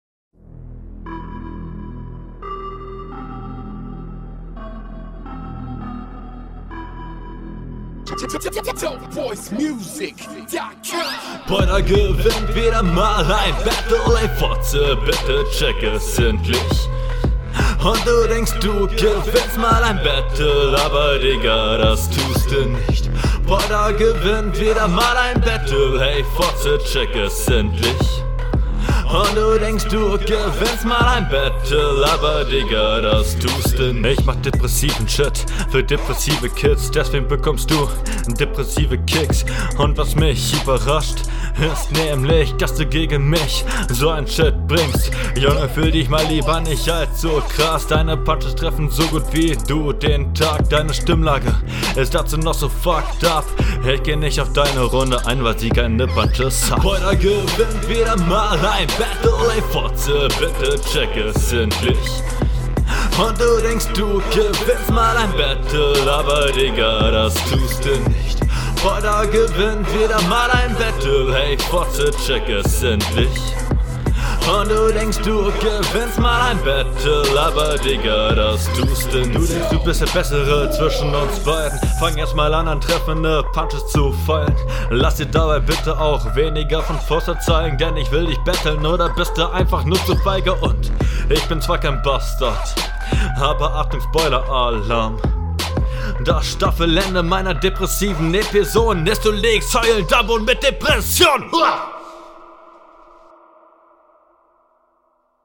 Flow: Definitiv solider als dein Gegner, die Lines kommen auch besser rüber, davon profitieren auch …